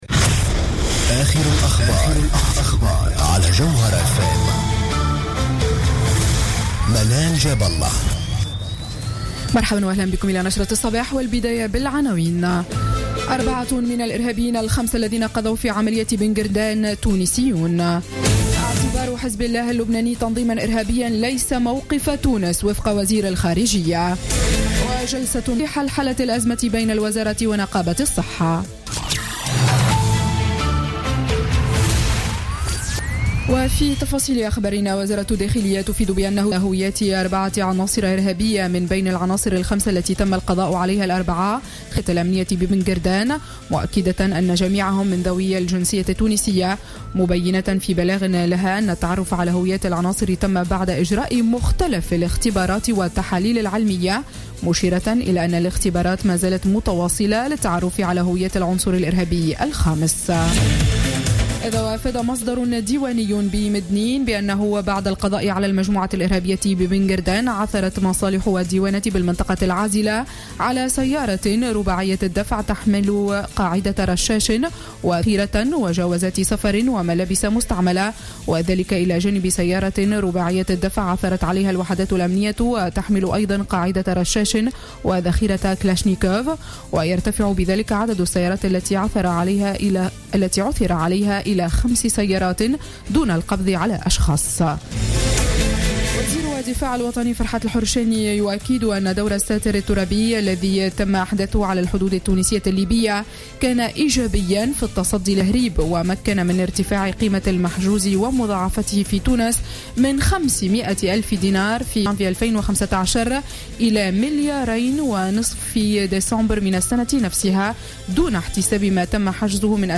Journal Info 07h00 du vendredi 4 mars 2016